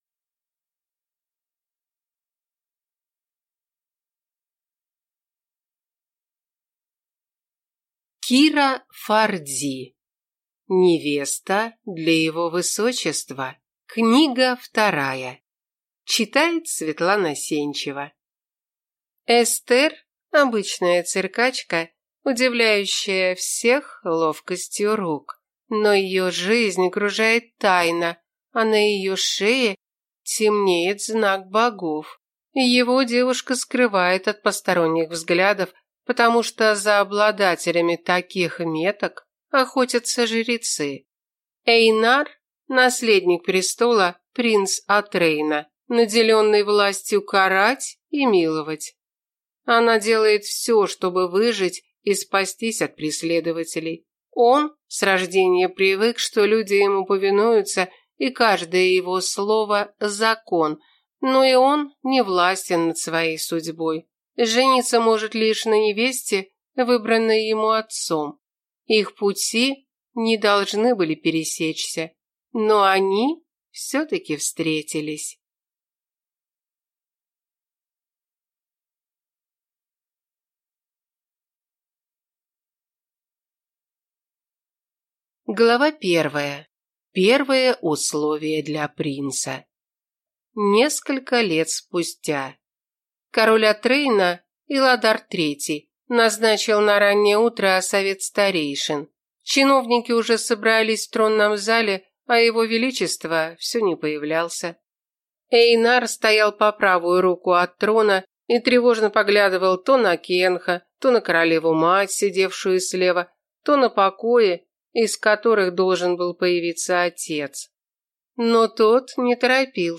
Аудиокнига Невеста для Его Высочества. Книга 2 | Библиотека аудиокниг